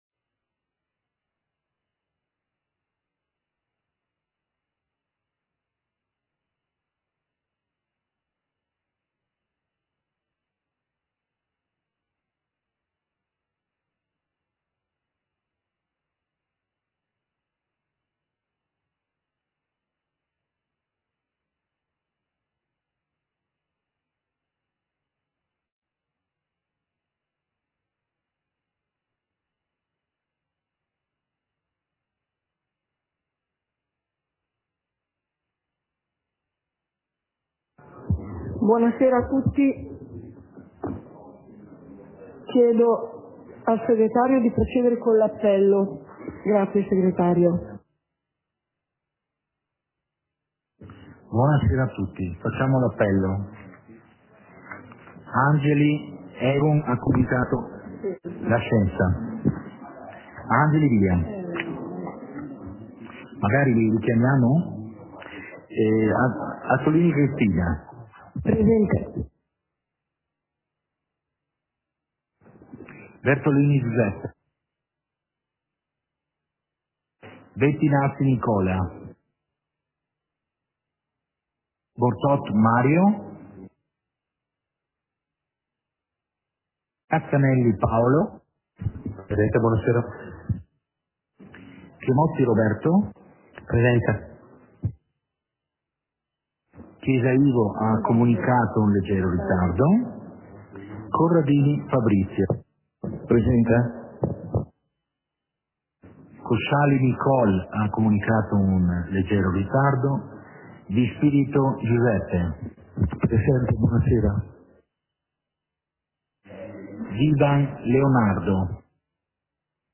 Seduta del consiglio comunale - 11.01.2023